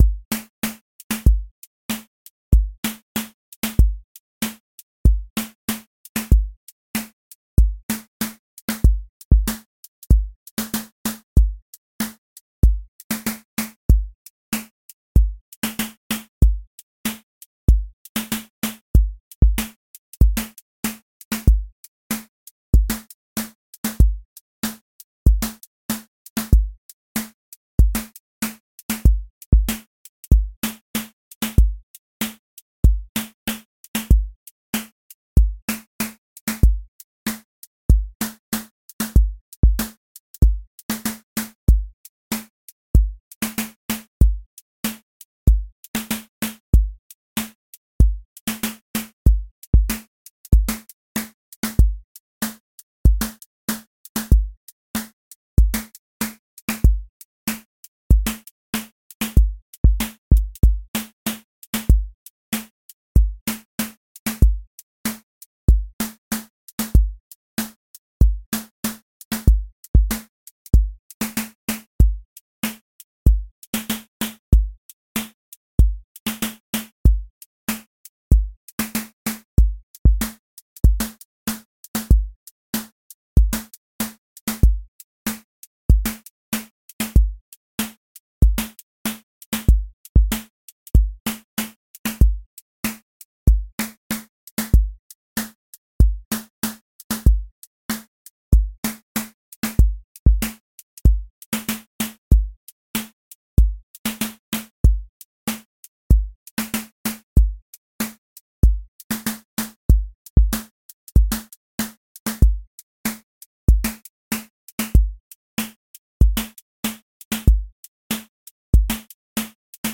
QA Listening Test boom-bap Template: boom_bap_drums_a
• voice_kick_808
• voice_snare_boom_bap
• voice_hat_rimshot
A long-form boom bap song with recurring sections, edits within the pattern every 4 bars, clear returns, and evolving pocket over two minutes.